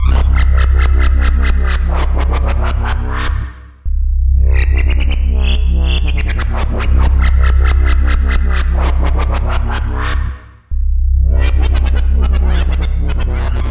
Tag: 154 bpm Hip Hop Loops Synth Loops 2.10 MB wav Key : Unknown